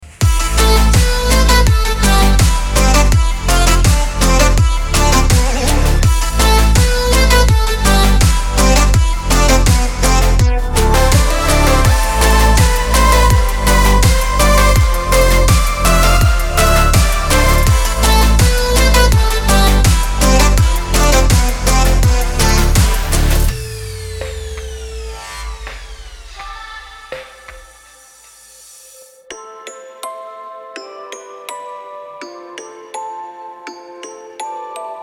• Качество: 320, Stereo
Жанр: Танцевальная/электронная музыка